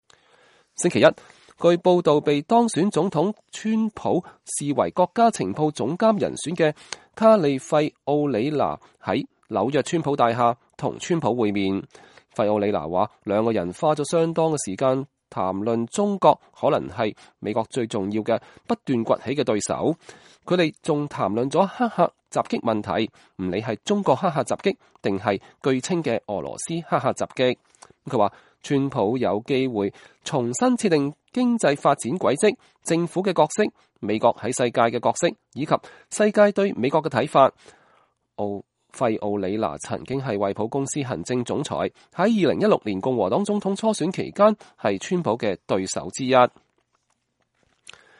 卡莉費奧里娜與當選總統川普會面後在川普大廈的大堂與傳媒談話。(2016年12月12日)